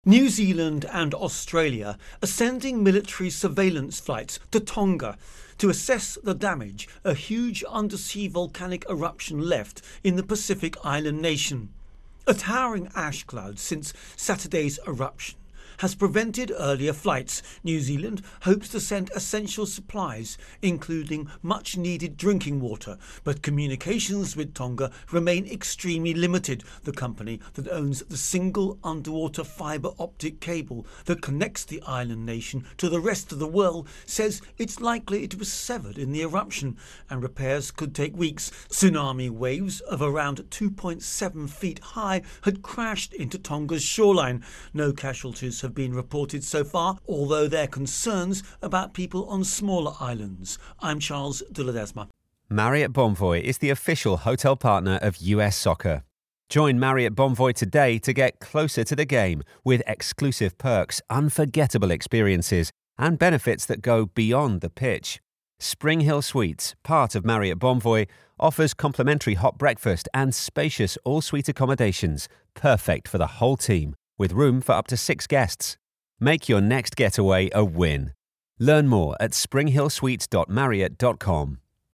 Tonga-Volcano Eruption Intro and Voicer